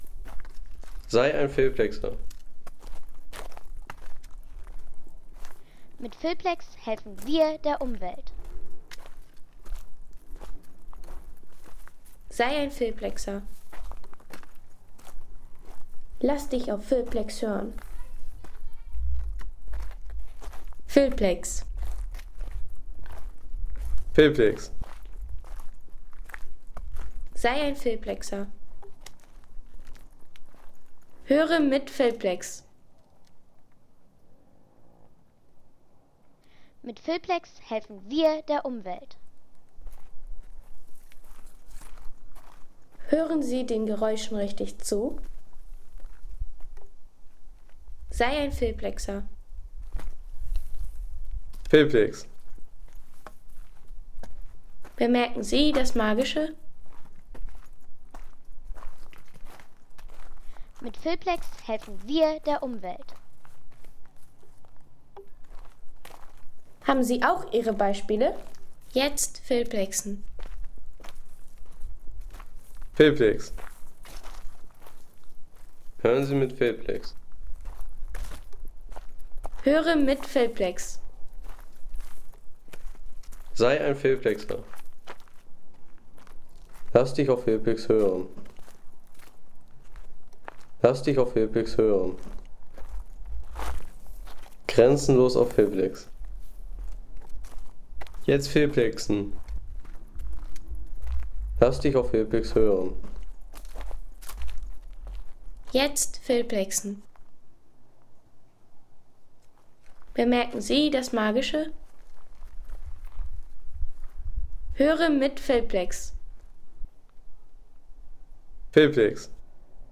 Wanderweg auf Montofarno